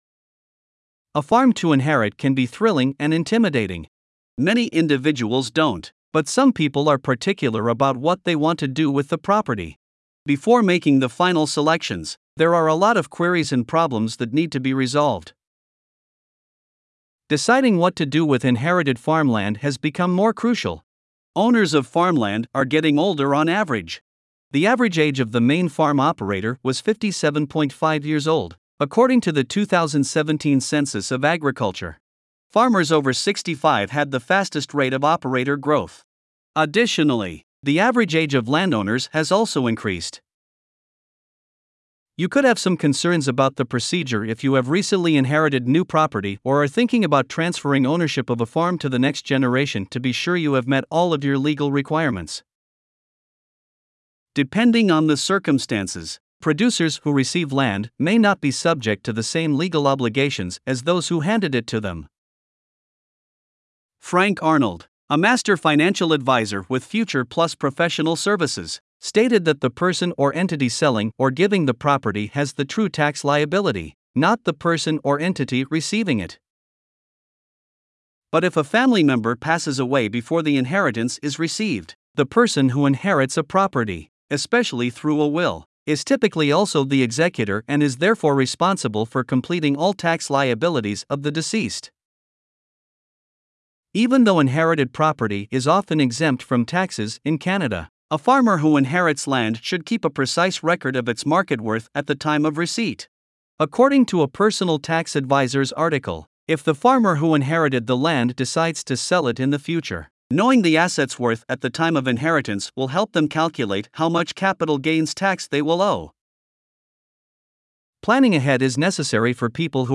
Voiceovers-Voices-by-Listnr_2.mp3